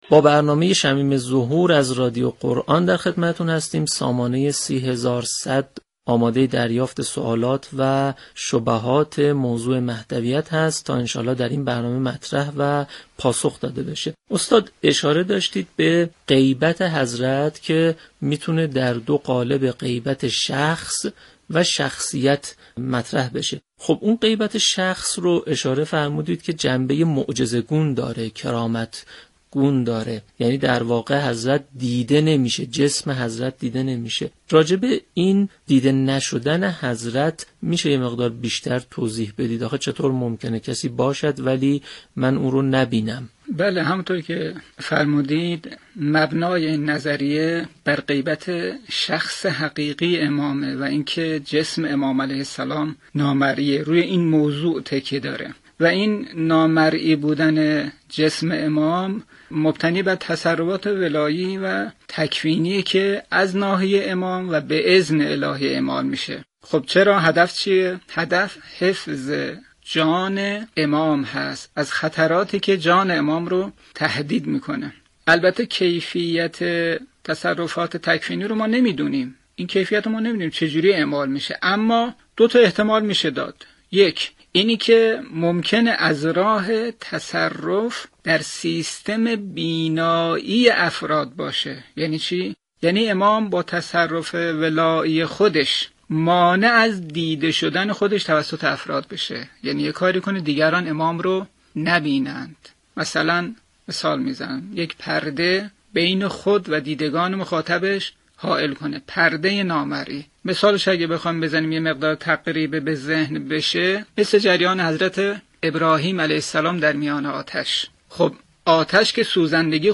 به گزارش پایگاه اطلاع رسانی رادیو قرآن؛ برنامه شمیم ظهور با همكاری بنیاد فرهنگی حضرت مهدی موعود(عج) وابسته به حوزه علمیه تهیه و تولید می شود كه بر این اساس علاوه بر اینكه به مباحث مورد اهمیت برای عموم مردم در حوزه مهدویت و ظهور می پردازد، به سوالات شنوندگان از طریق پیامك و تماس های تلفنی مخاطبان توسط حجت الاسلام والمسلمین